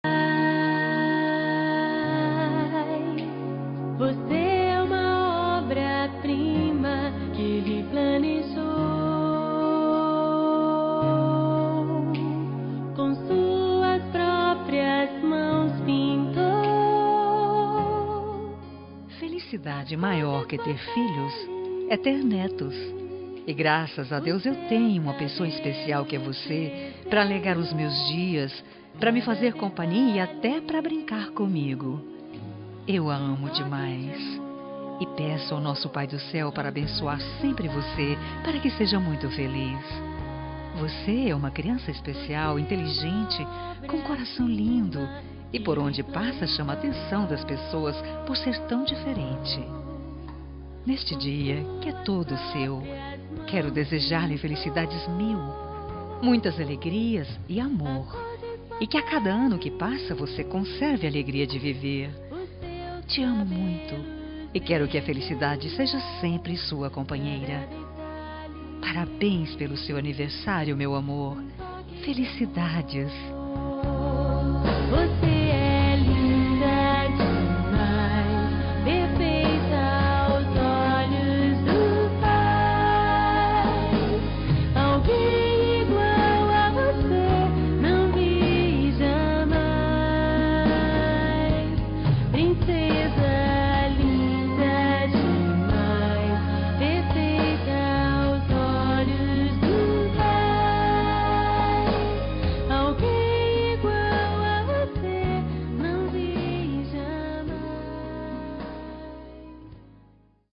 Aniversário de Neta – Voz Feminina – Cód: 131024